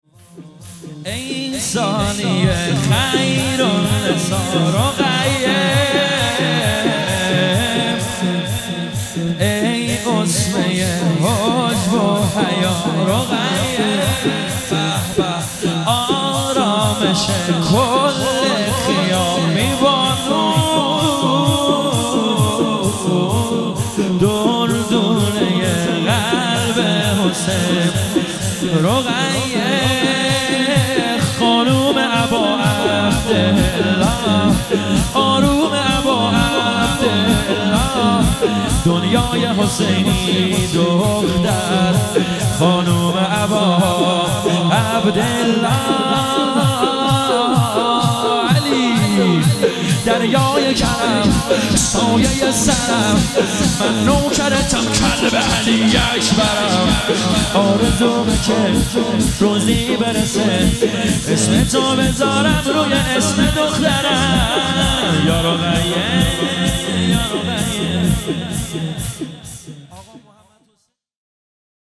شور - ای ثانی خیرنسا رقیه
مجلس روضه فاطمیه